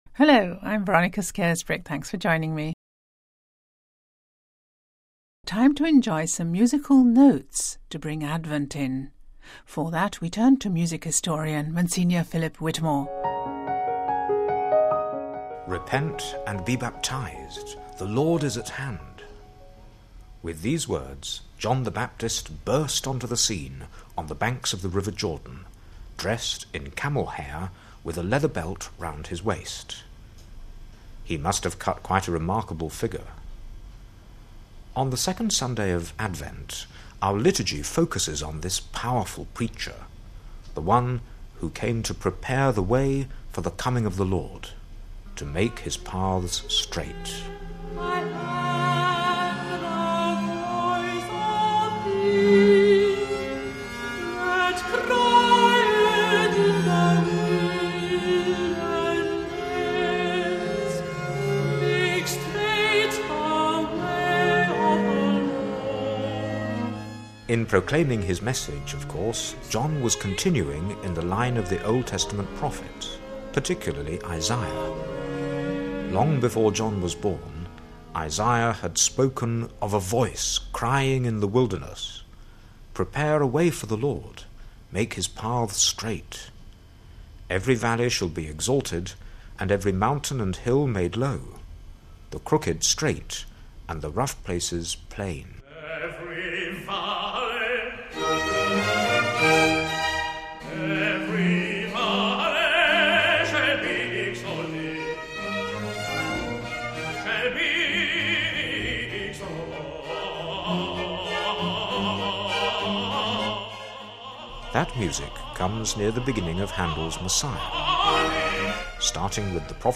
Heavenly hymns